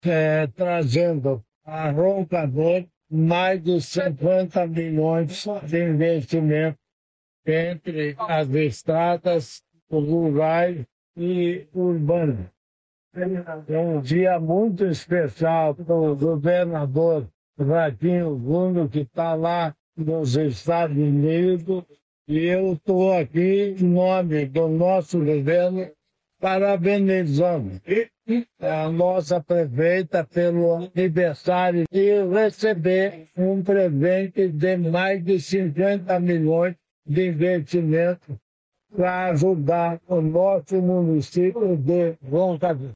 Sonora do governador em exercício, Darci Piana, sobre os investimentos em Roncador